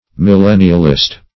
Millennialist \Mil*len"ni*al*ist\, n.